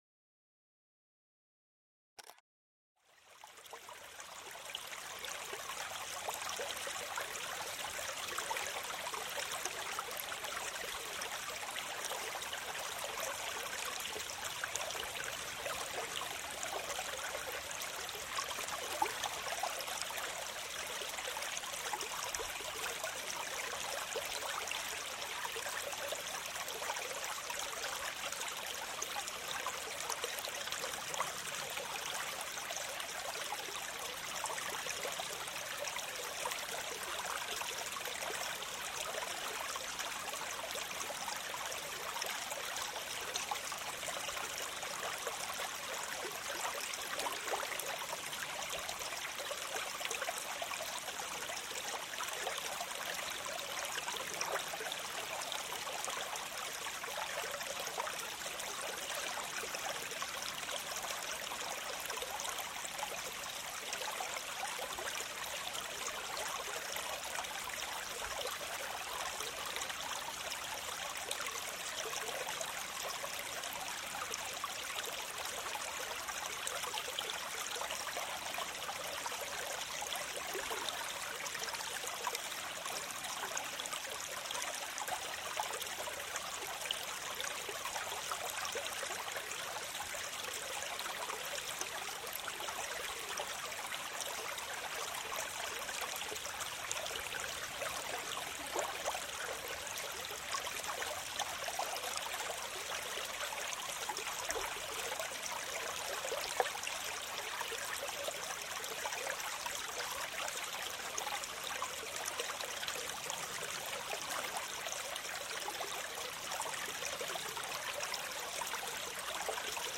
GEIST-ENTSPANNUNG: Kleiner Wasserfall-Flüstern mit leisen Tropfen